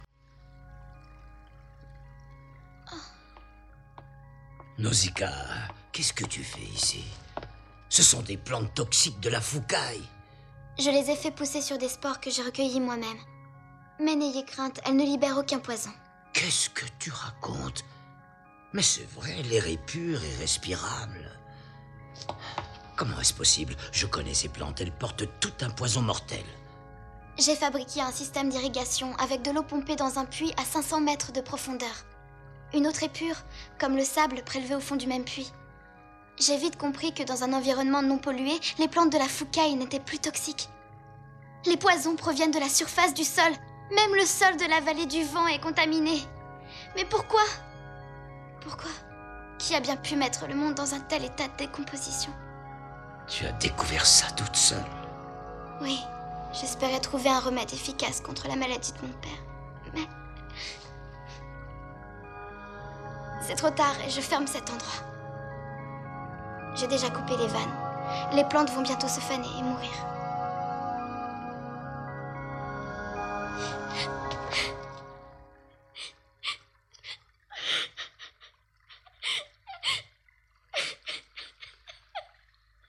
La fillette apprend à Maître Yupa que la forêt n’est toxique que par son contact avec le sol saturé de pollution par les hommes. Son pleur, en fin d’extrait, à l’évocation du souvenir de la mort son père, témoigne également du peu d’espoir restant pour la Terre et l’humanité.